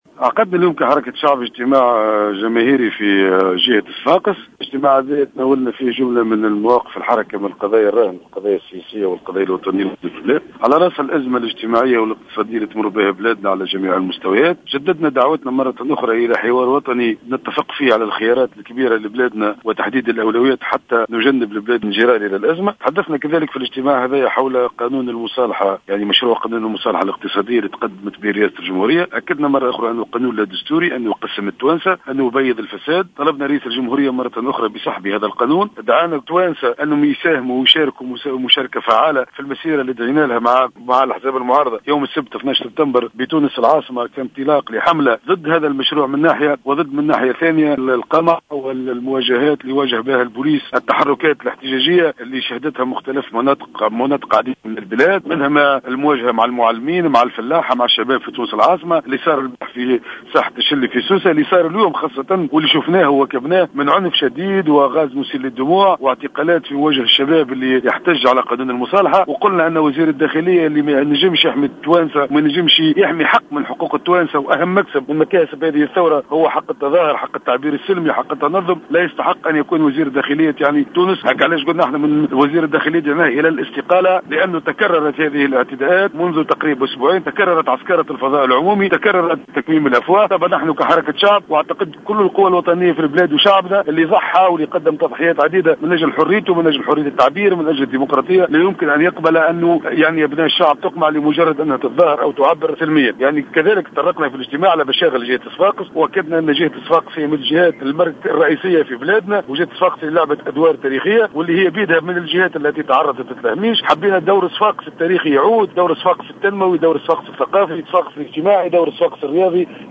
طالب أمين عام حركة الشعب زهير المغزاوي في تصريح للجوهرة أف أم اليوم الأحد 06 سبتمبر 2015 وزير الداخلية محمد ناجم الغرسلي بالاستقالة وذلك على خلفية العنف المسلط على المتظاهرين خلال الاحتجاجات التي نظمت في عدة جهات تنديدا بمشروع قانون المصالحة الاقتصادية.